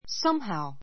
somehow sʌ́mhau サ ム ハウ 副詞 ❶ 何とかして, どうにかして （in some way） ⦣ somehow or other ともいう.